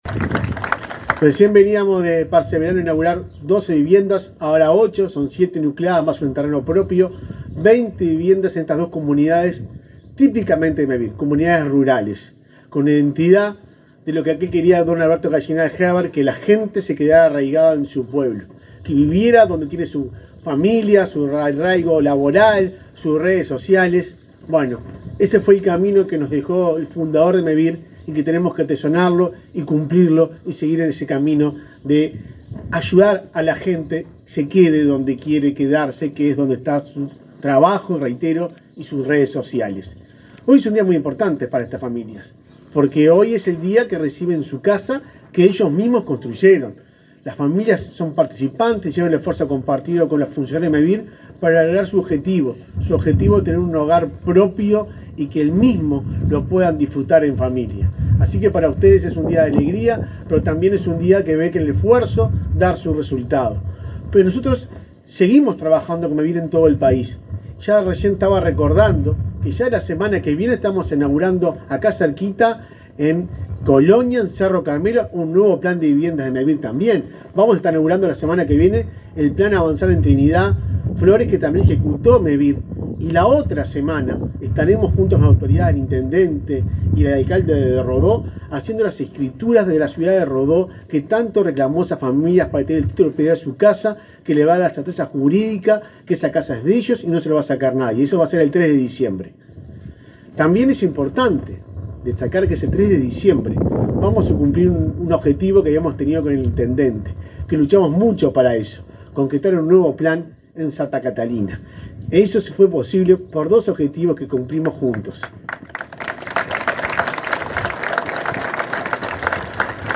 Palabra de autoridades en acto de Mevir 20/11/2024 Compartir Facebook X Copiar enlace WhatsApp LinkedIn El presidente de Mevir, Juan Pablo Delgado, y el ministro interino de Vivienda, Tabaré Hackembruch, se expresaron, durante el acto de inauguración de viviendas rurales en Pueblo Castillo, departamento de Soriano.